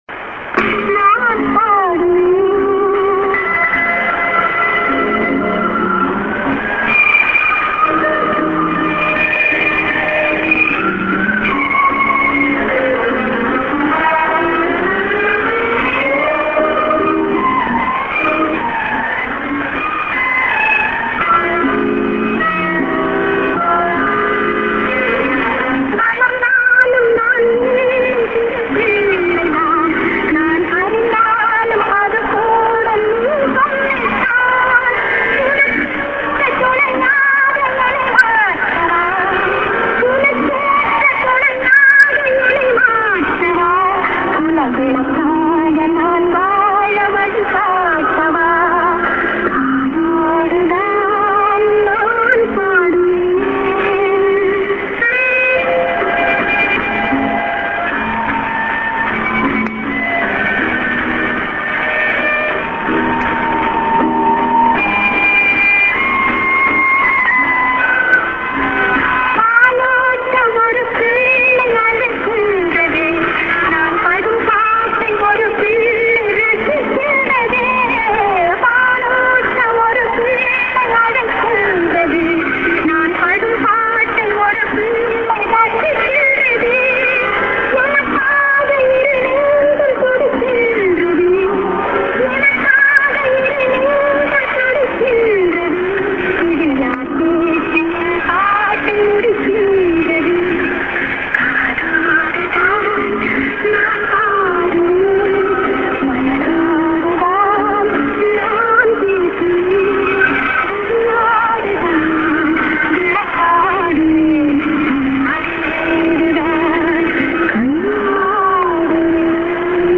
via DTK Julich St. music->02'10":ID(man)->03'15":ID(women)->04'15":ID->06'00":ADDR(man)->